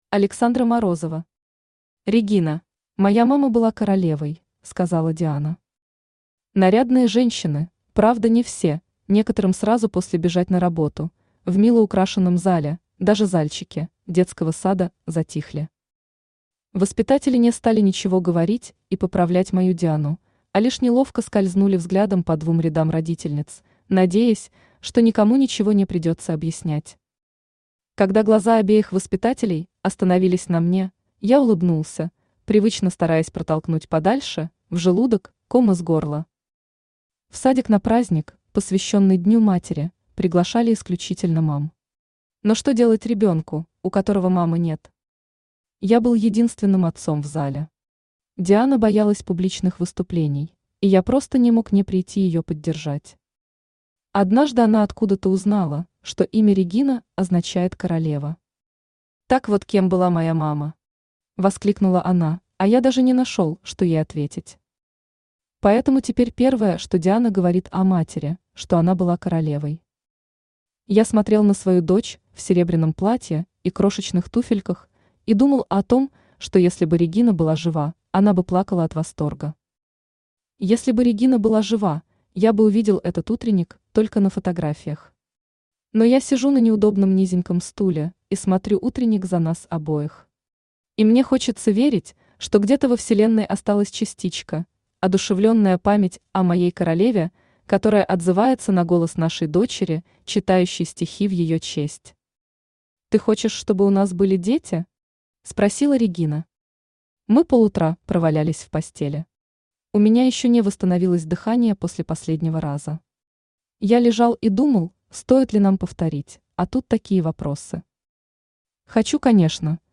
Aудиокнига Регина Автор Александра Морозова Читает аудиокнигу Авточтец ЛитРес.